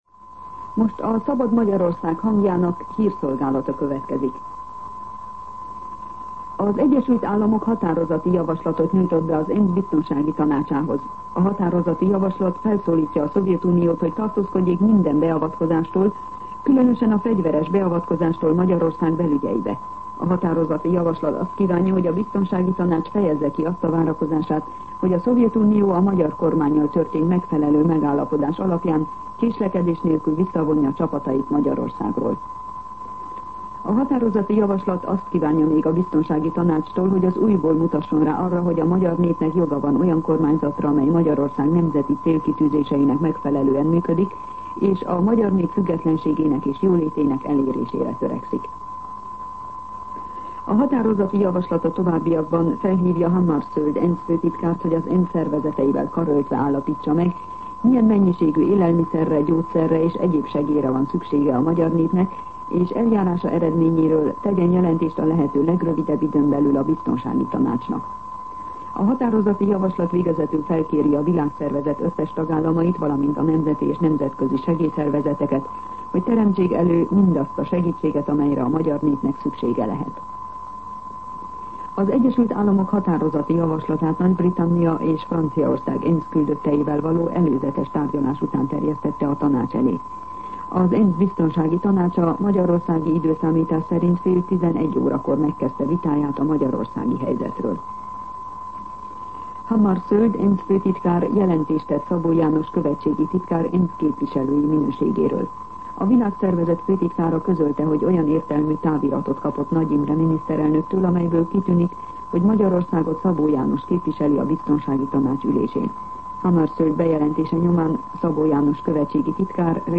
Hírszolgálat
MűsorkategóriaHírszolgálat